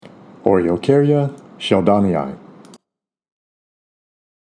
Pronunciation/Pronunciación:
O-re-o-cár-ya shel-dón-i-i